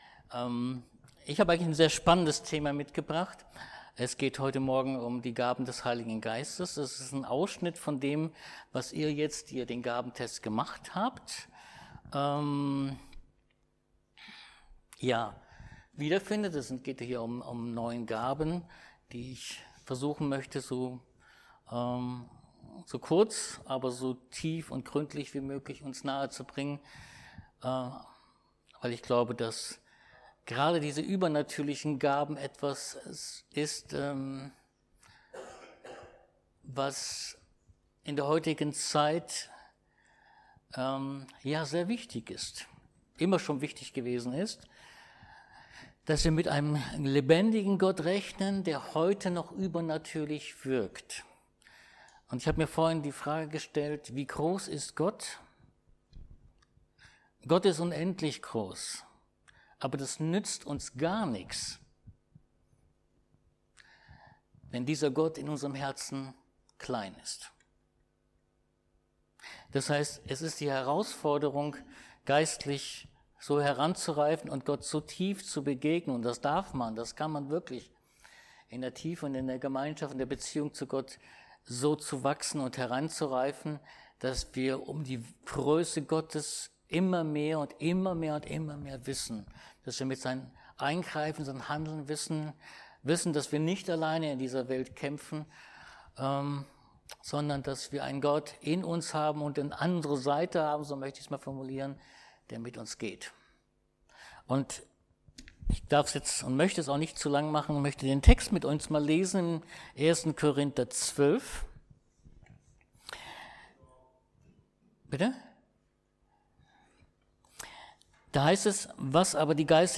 Kor 12 Dienstart: Predigt Gott hat uns Gaben gegeben zum Bau seiner Gemeinde.